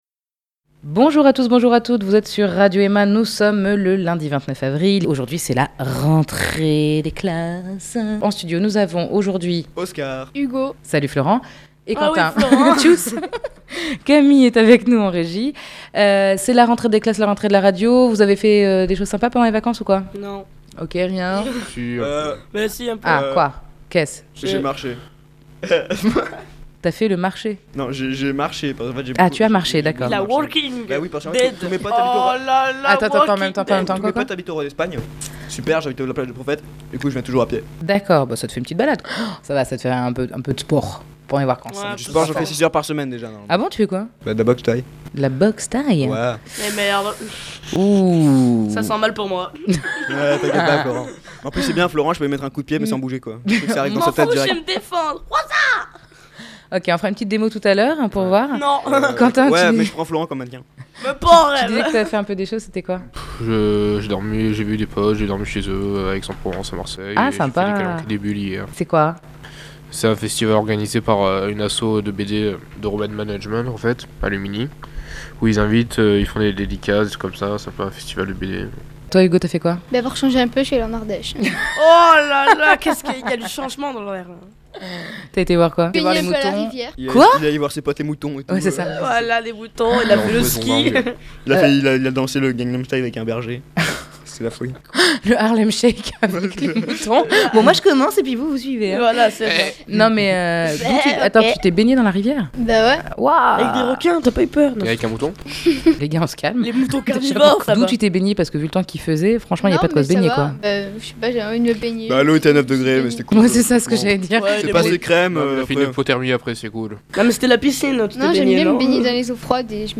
Voici une émission haute en couleurs, volume et bonne humeur!